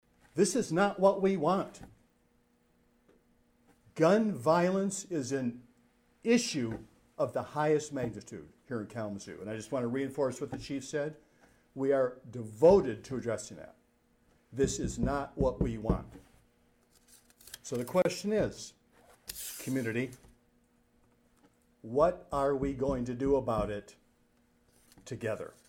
Also during the press conference, Kalamazoo City Mayor David Anderson expressed his condolences to those affected by the shooting along with his gratitude to the officers of KDPS, all the while trying to find words to make Sunday’s event make sense.